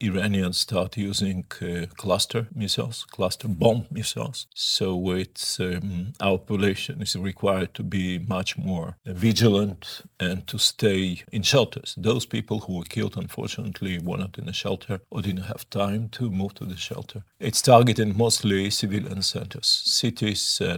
ZAGREB - Dok se cijeli svijet pita koliko će trajati rat na Bliskom istoku i kakve će globalne posljedice ostaviti, odgovore na ta pitanja potražili smo u Intervjuu tjedna Media servisa od izraelskog veleposlanika u Zagrebu Garyja Korena.